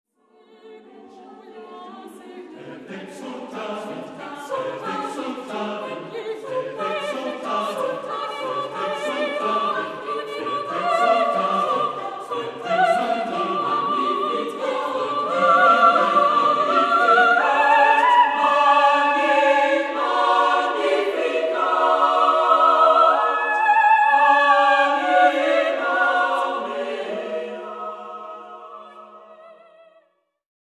SATB (4 voices mixed).
Contemporary. Sacred.